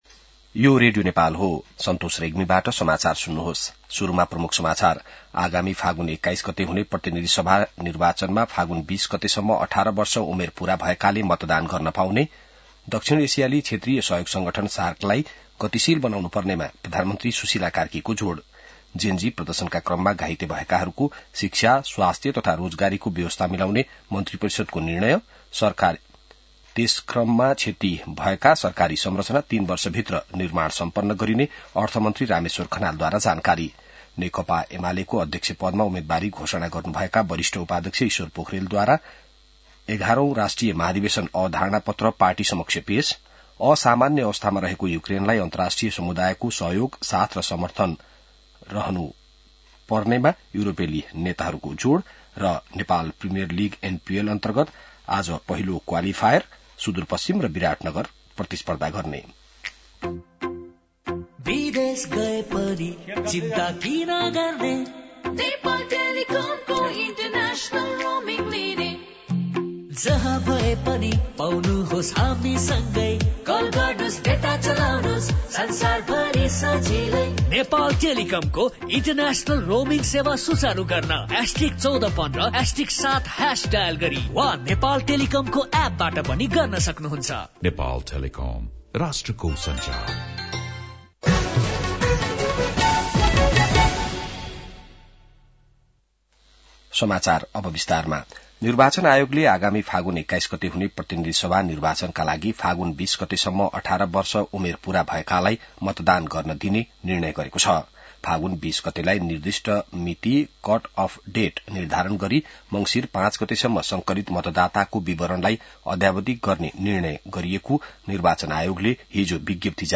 बिहान ७ बजेको नेपाली समाचार : २३ मंसिर , २०८२